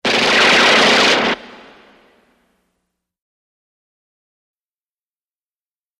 WEAPONS - FUTURISTIC MACHINE GUN: EXT: Single long burst with reverb.